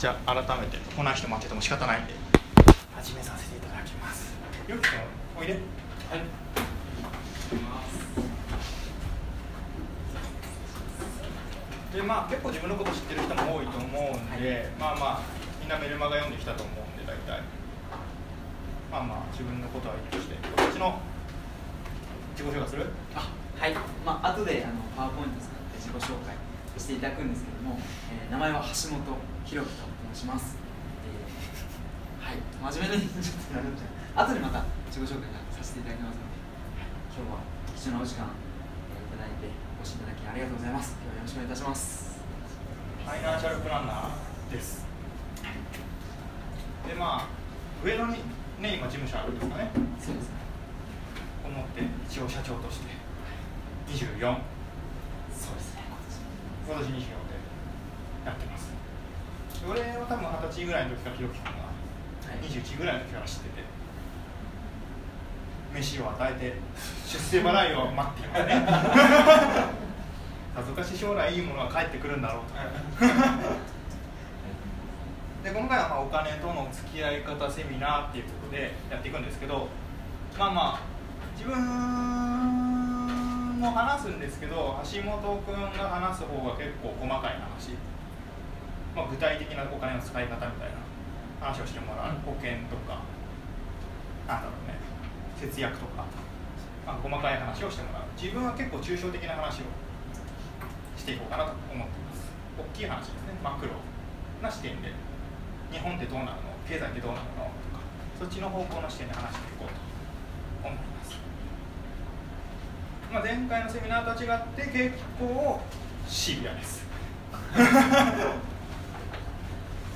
お金との付き合い方セミナー前半 - Pay it forward